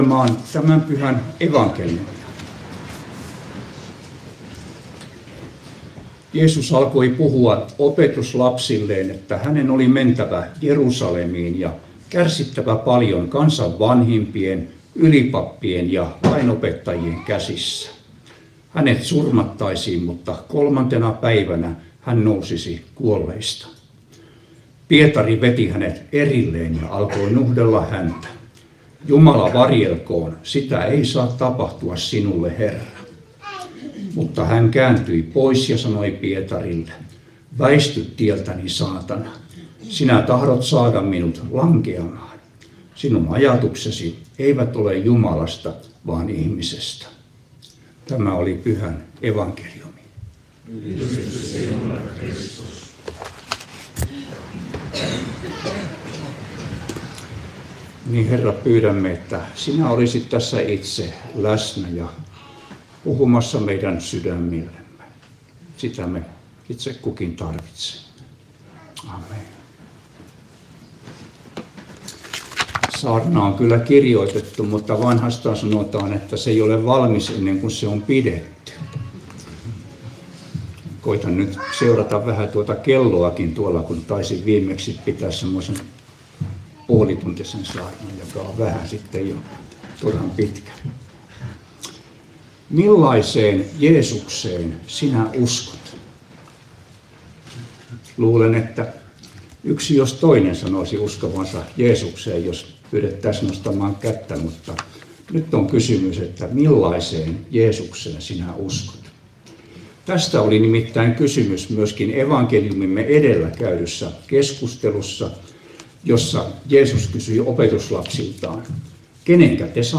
Pori